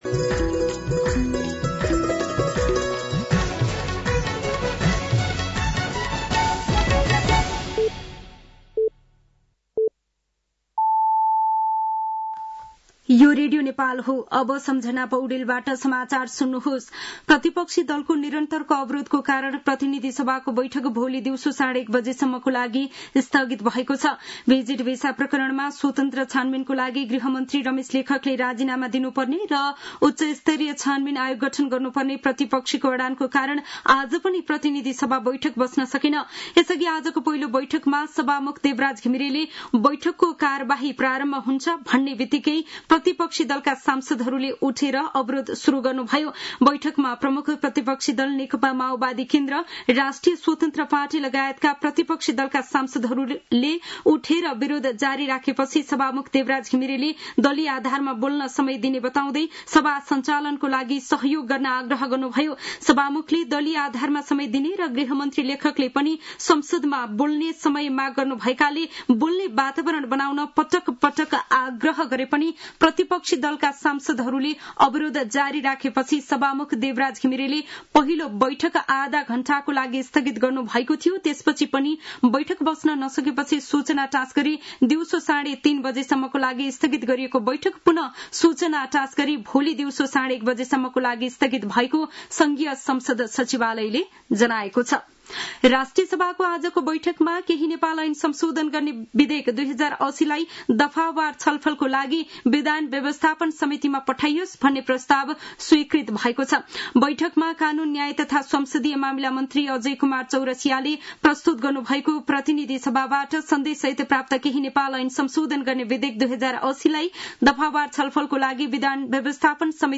साँझ ५ बजेको नेपाली समाचार : १४ जेठ , २०८२